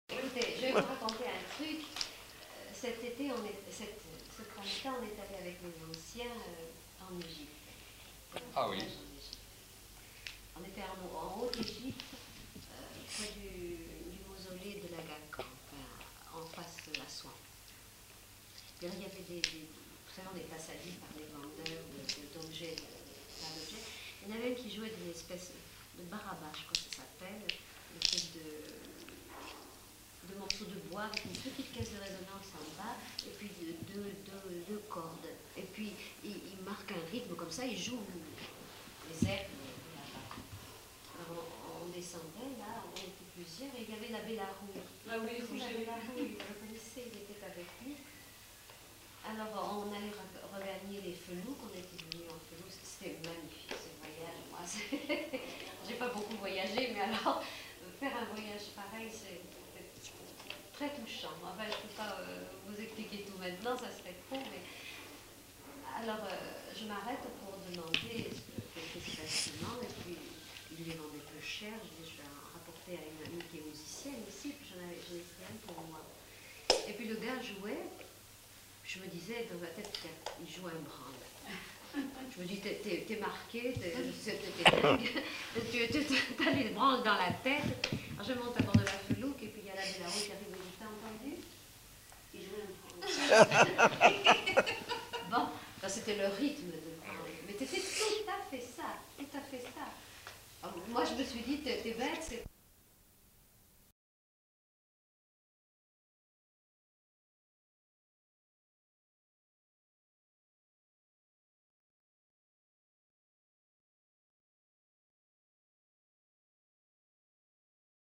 Aire culturelle : Béarn
Lieu : Bielle
Genre : témoignage thématique